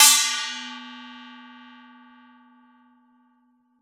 JJPercussion (105).wav